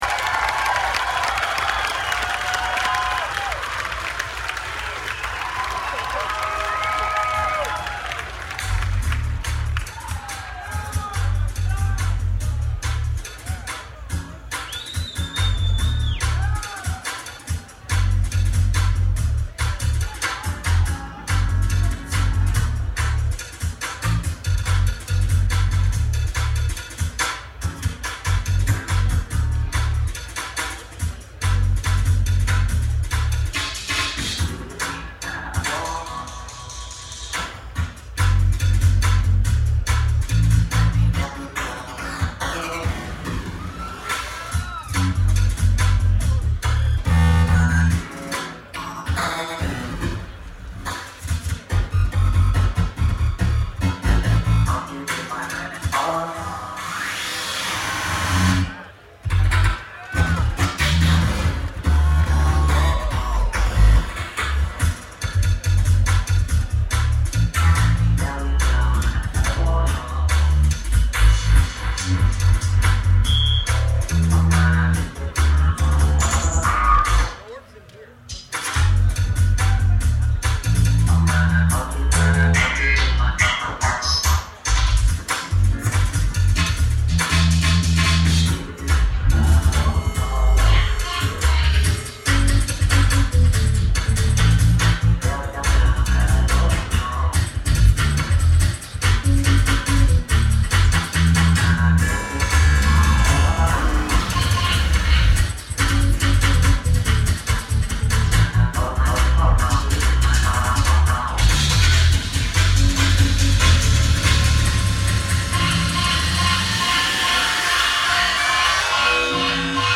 event Coachella Valley Music and Arts Festival